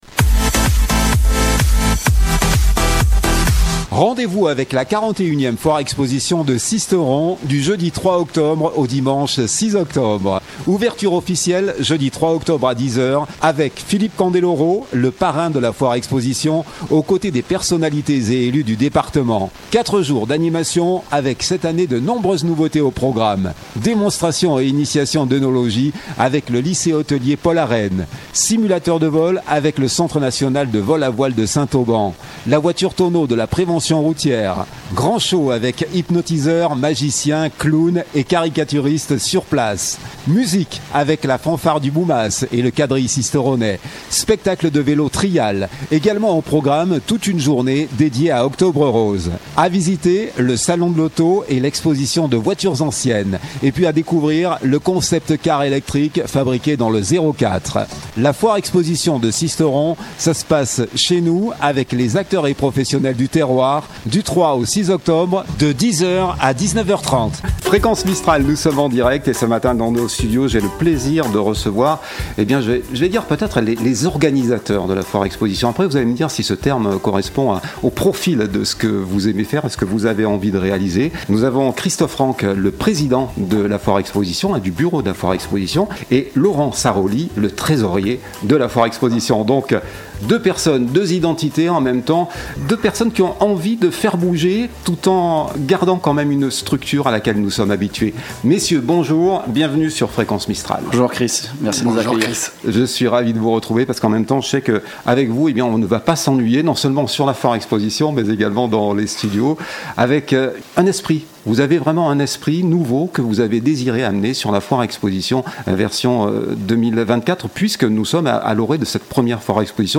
EMISSION FOIRE EXPOSITION DE SISTERON 2024.mp3 (36.43 Mo)